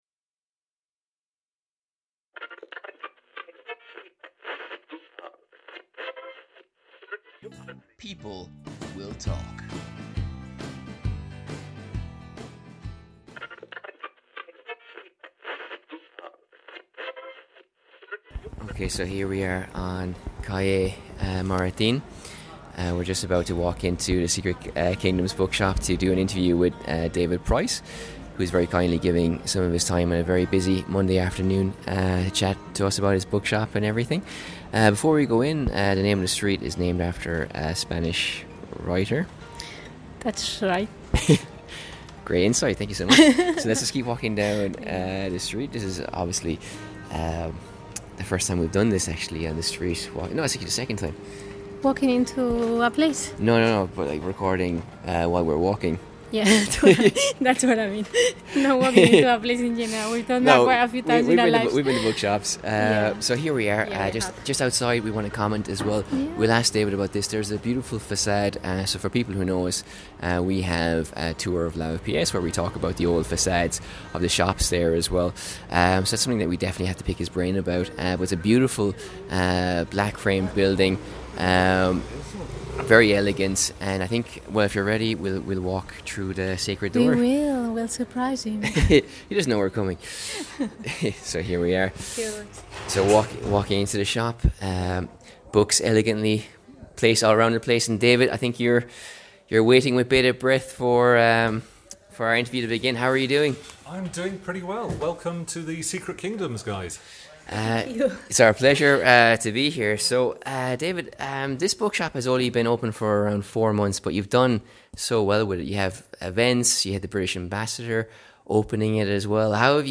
Through the doors of The Secret Kingdoms: an interview